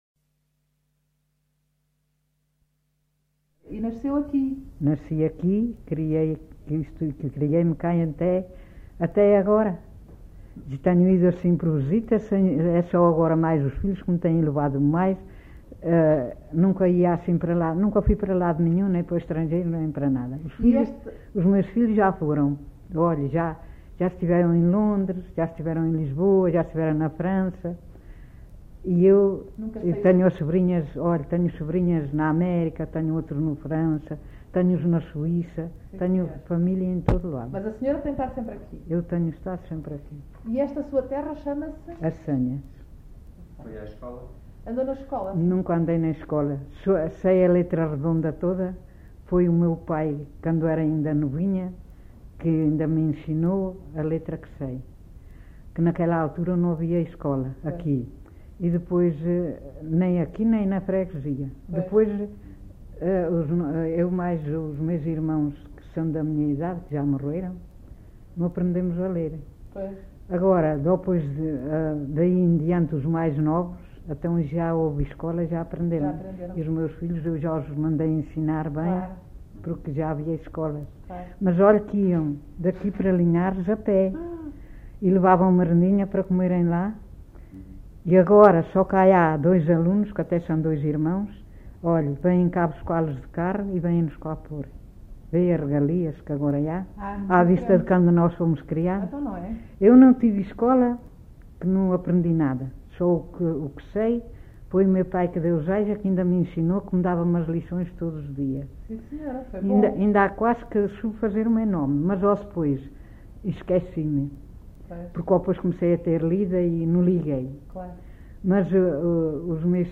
LocalidadeAssanhas (Celorico da Beira, Guarda)